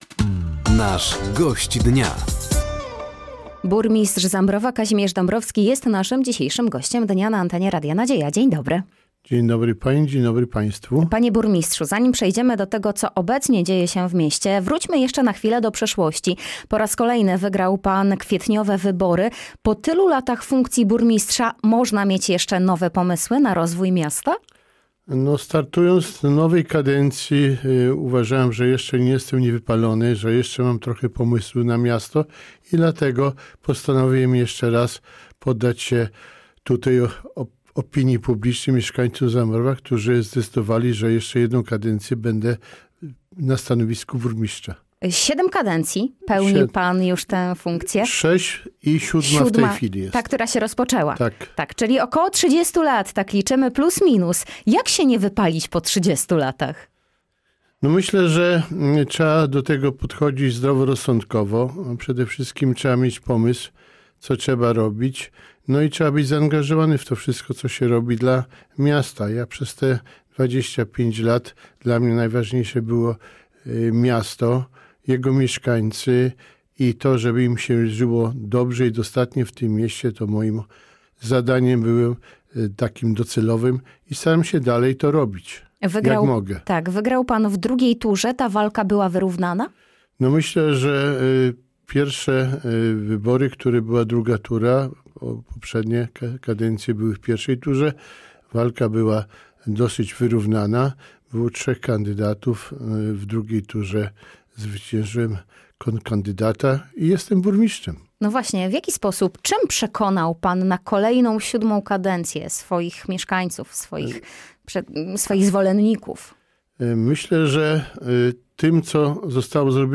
Studio Radia Nadzieja odwiedził Kazimierz Dąbrowski, burmistrz Zambrowa.